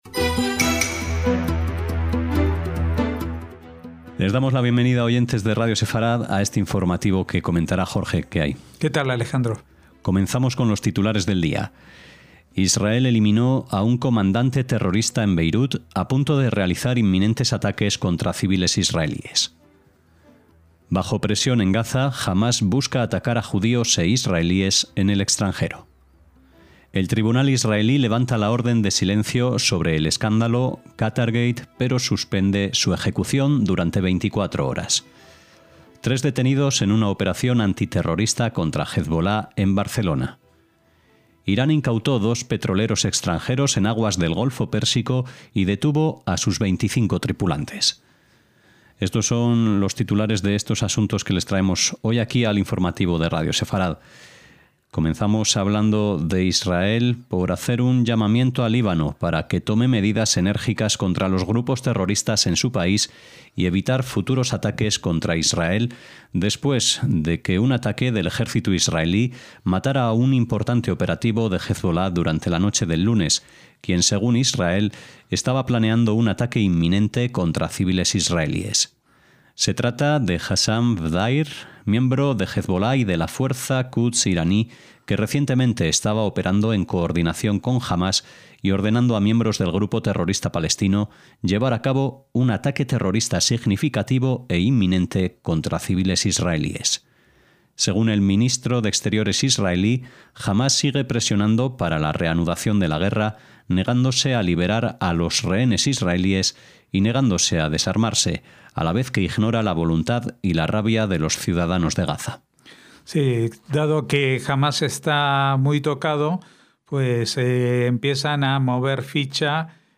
NOTICIAS - Titulares de hoy: Israel eliminó a un comandante terrorista en Beirut, a punto de realizar inminentes ataques contra civiles israelíes. Bajo presión en Gaza, Hamás busca atacar a judíos e israelíes en el extranjero.